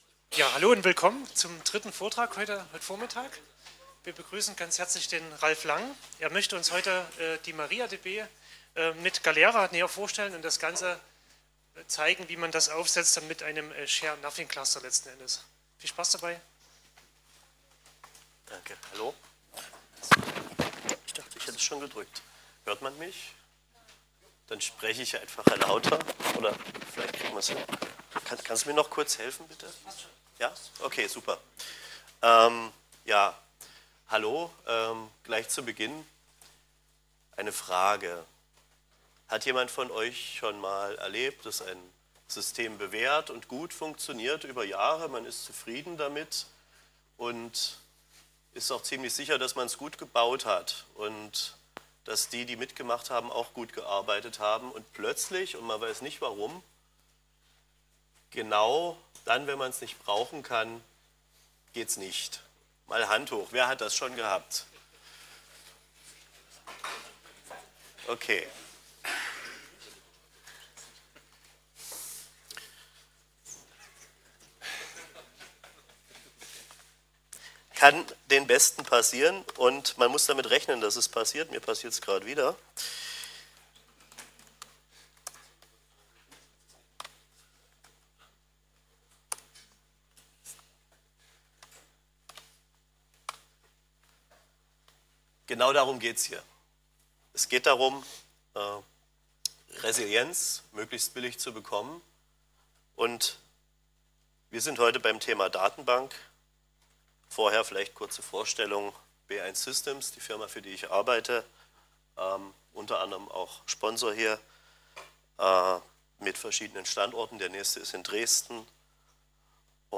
CLT2019 · Lecture: Hochverfügbar mit MariaDB und Galera
Chemnitzer Linux-Tage 2019 · Lecture: Hochverfügbar mit MariaDB und Galera